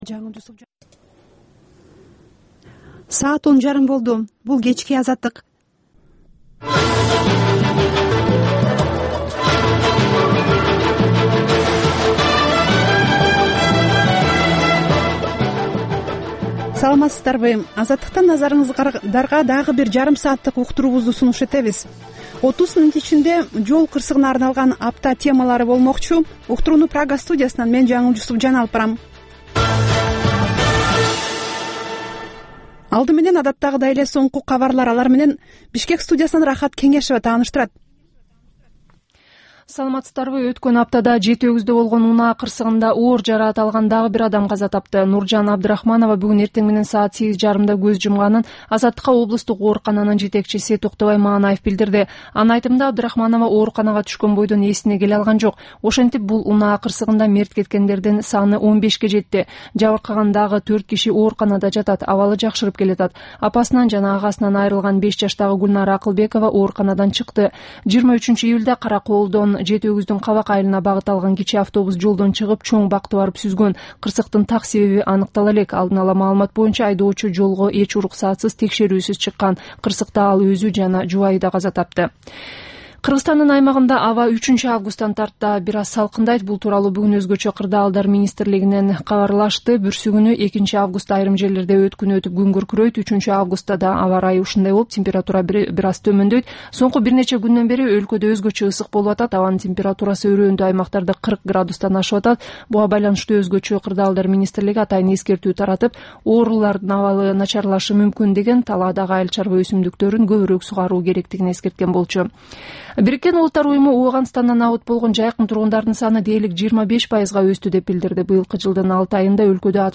"Азаттык үналгысынын" бул кечки жарым сааттык экинчи берүүсүнүн кайталосоу «Арай көз чарай» түрмөгүнүн алкагындагы тегерек үстөл баарлашуусу, репортаж, маек, талкуу, аналитикалык баян, сереп, угармандардын ой-пикирлери, окурмандардын э-кат аркылуу келген пикирлеринин жалпыламасы жана башка берүүлөрдөн турат.